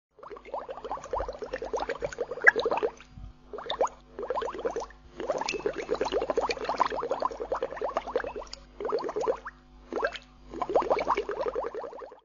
Download Free Bubble Sound Effects
Bubble